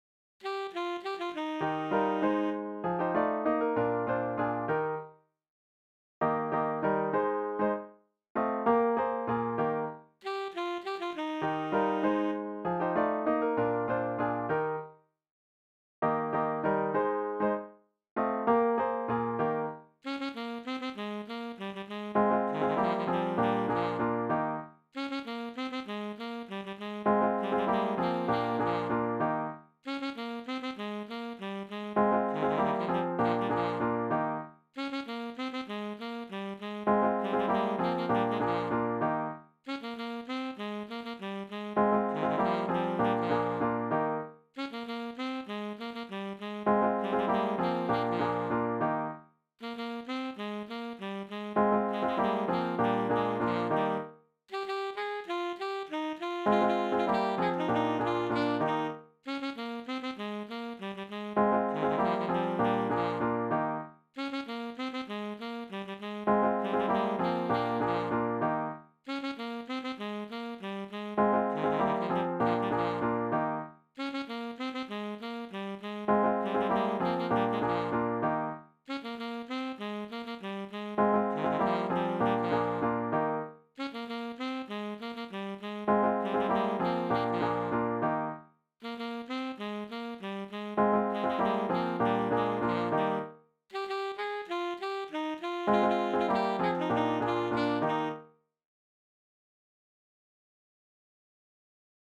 E flat major For: SATB+ Solo
Key: E flat major